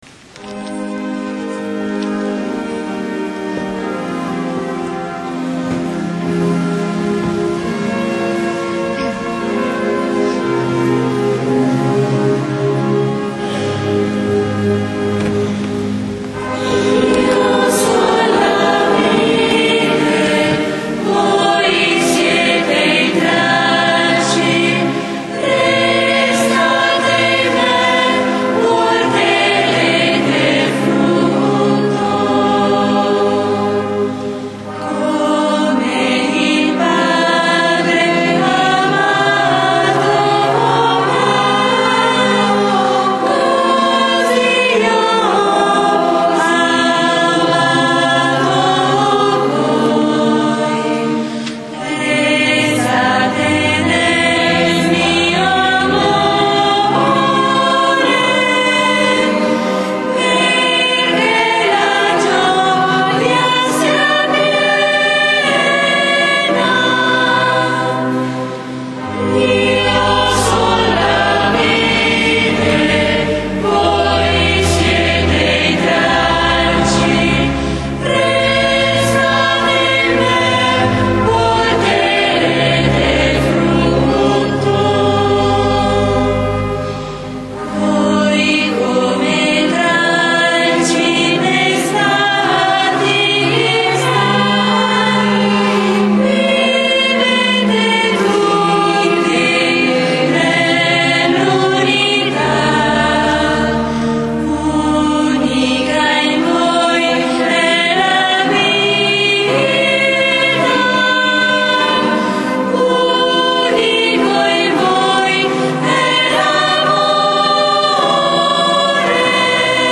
GIOVEDI SANTO -Celebrazione della Cena del Signore
canti: La vera vite - Cantico di Simeone